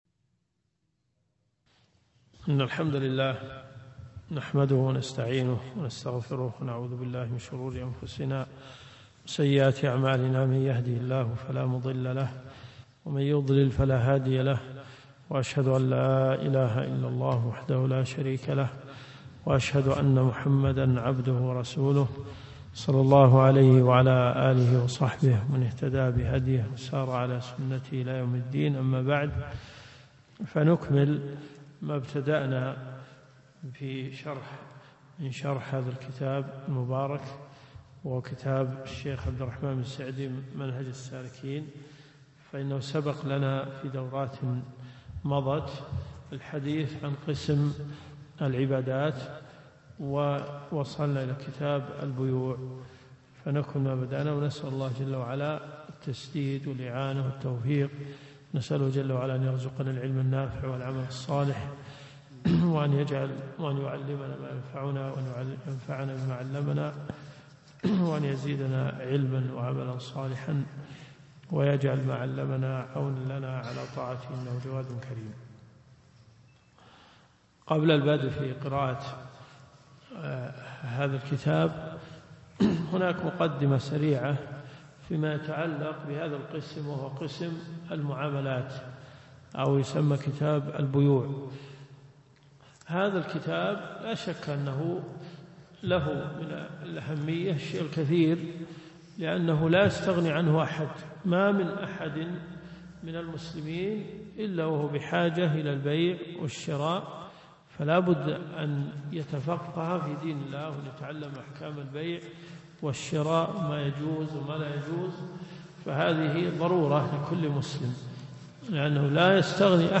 الدرس في الدقيقة 16 . المدينة المنورة . جامع البلوي